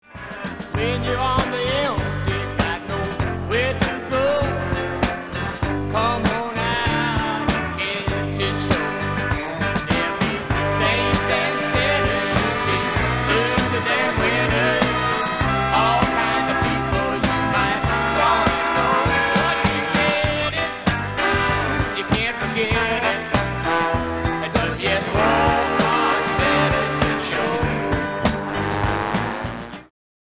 historical country rock, mixed with gospel and blues
(recorded live)